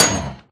mob / blaze / hit2.ogg
hit2.ogg